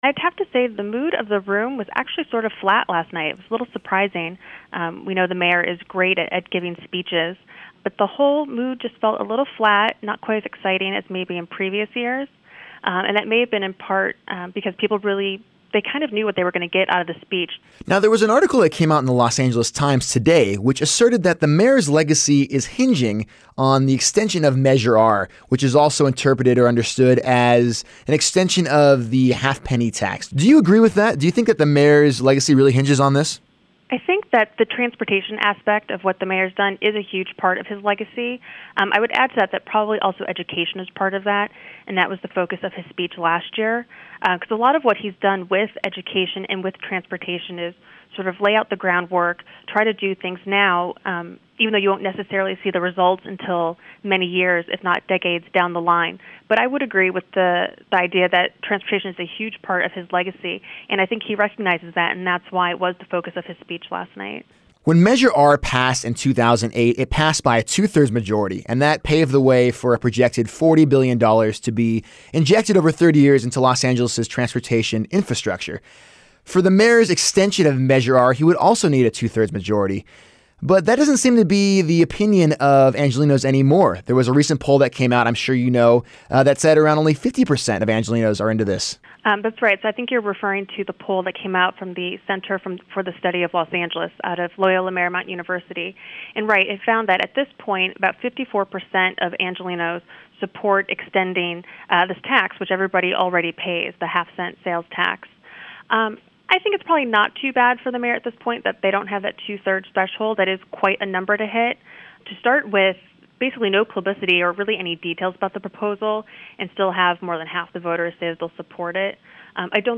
Mayor Antonio Villaraigosa called for an extension of Measure R -- Los Angeles' $40 billion transportation upgrade fueled by a half-penny tax -- at his 'State of the City' address last night at Paramount Studios auditorium.